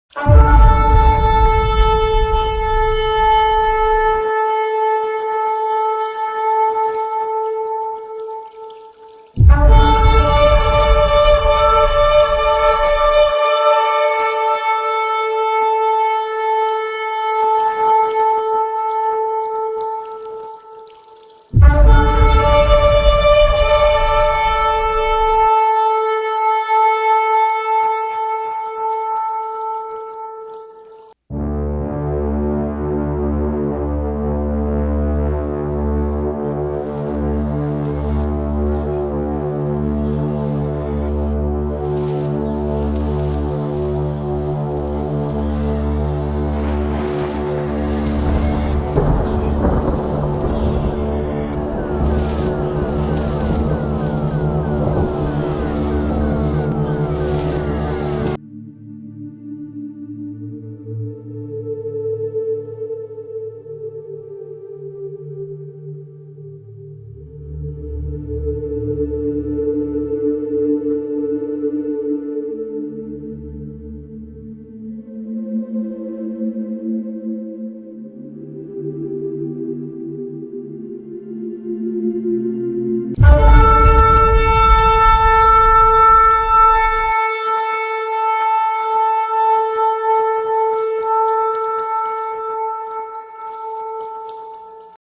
SHOFAR.mp3